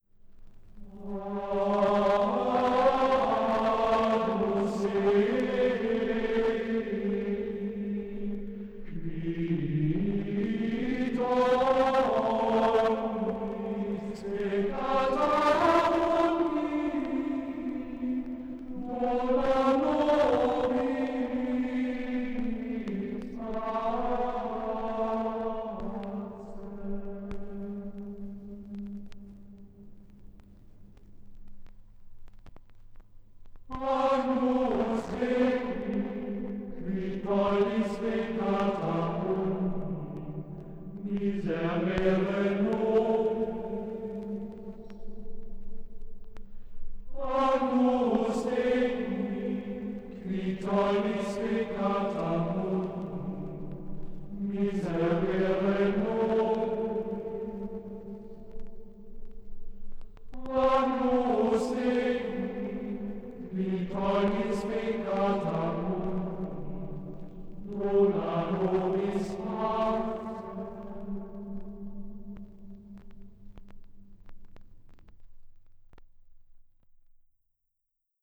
Gesang: Schola gregoriana im Auftrag der Robert-Schumann-Hochschule Düsseldorf
aufgenommen in der Klosterkirche Knechtsteden